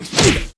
带人声的空手击中zth070511.wav
通用动作/01人物/03武术动作类/带人声的空手击中zth070511.wav
• 声道 單聲道 (1ch)